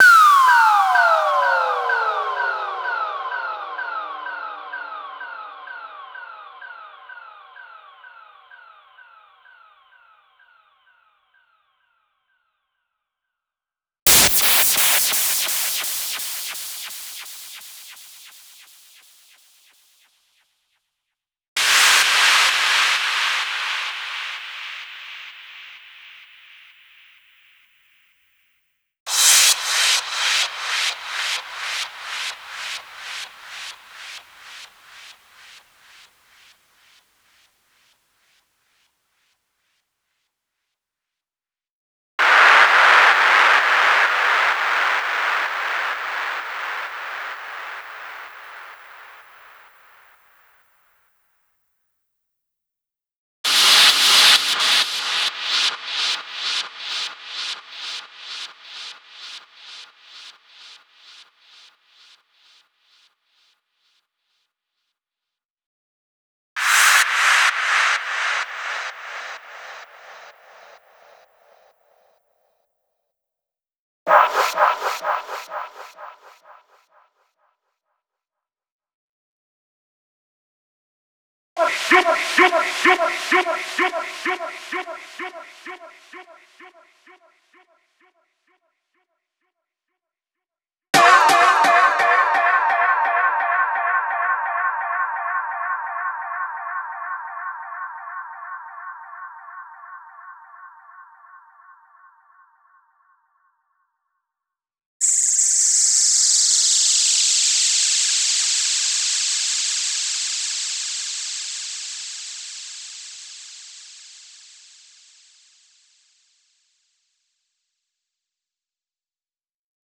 IMPACT.wav